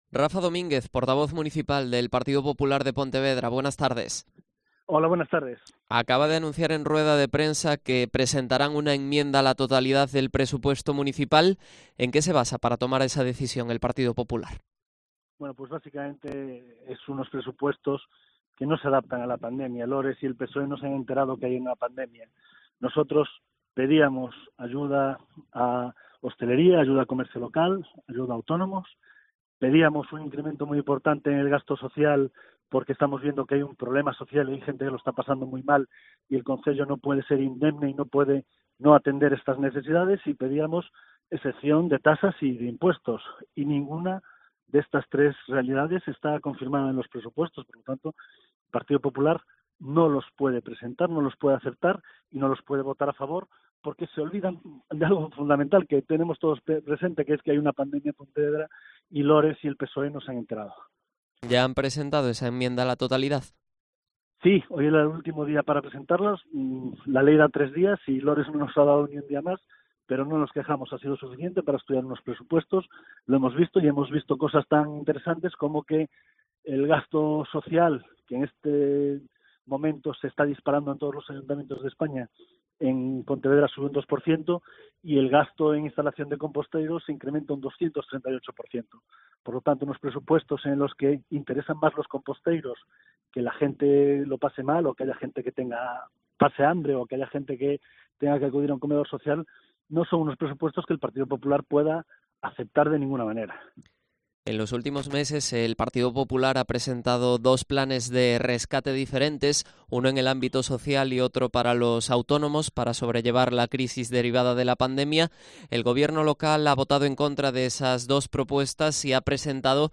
AUDIO: Entrevista a Rafa Domínguez, portavoz municipal del PP de Pontevedra